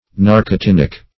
Search Result for " narcotinic" : The Collaborative International Dictionary of English v.0.48: Narcotinic \Nar`co*tin"ic\ (n[aum]r`k[-o]*t[i^]n"[i^]k), a. Pertaining to narcotine.
narcotinic.mp3